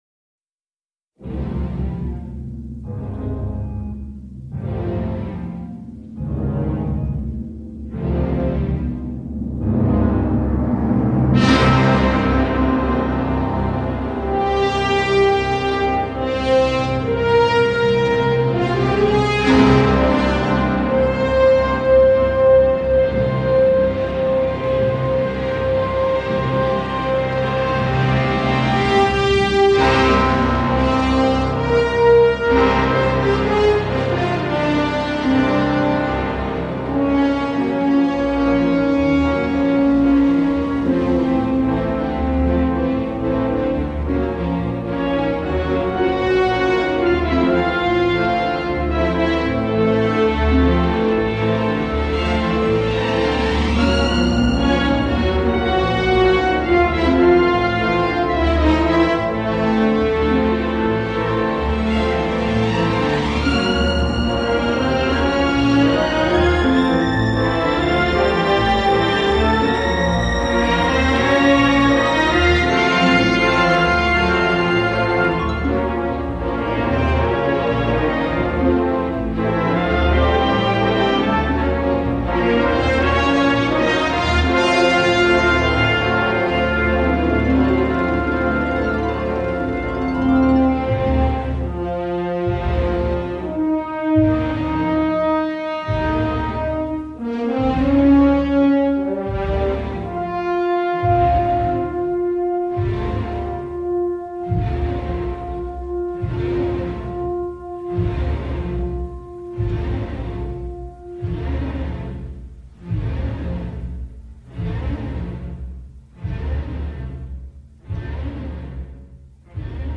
Groovy et flippant, intemporel et typé 70’s, indispensable !